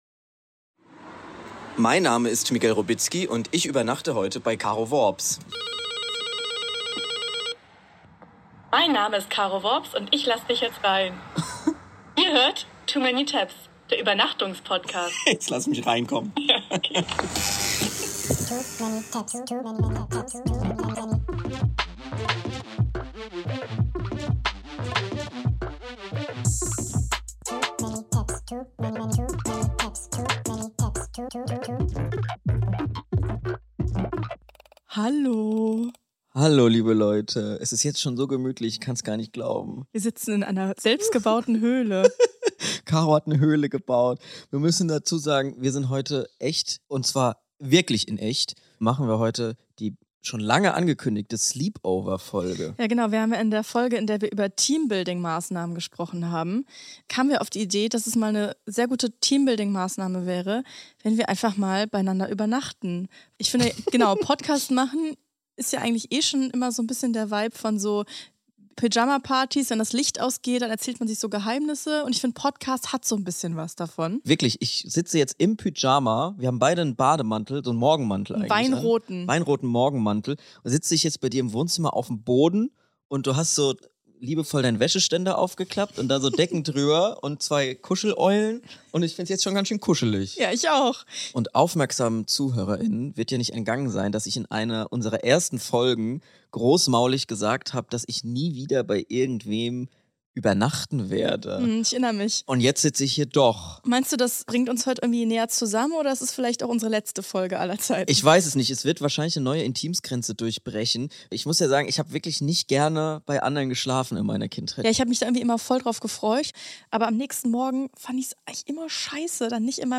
In dieser Spezial-Übernachtungsfolge kommt der Podcast direkt aus der selbstgebauten Bettdecken-Höhle.